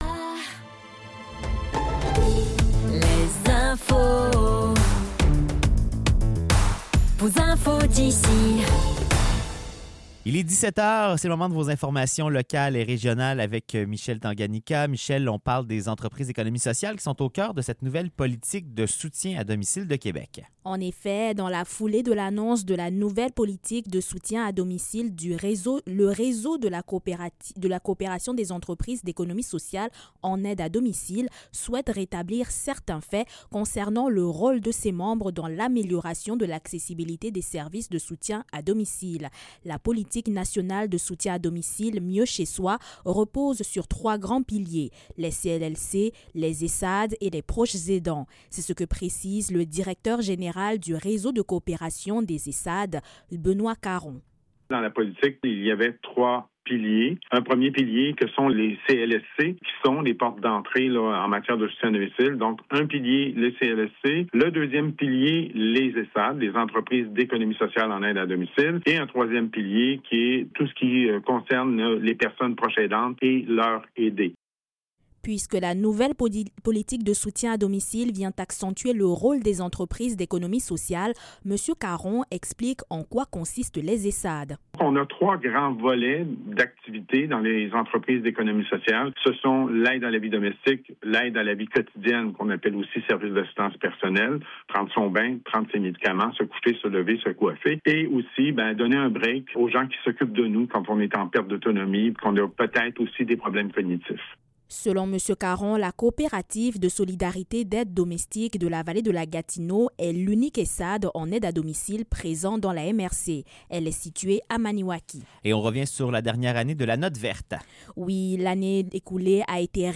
Nouvelles locales - 16 février 2026 - 17 h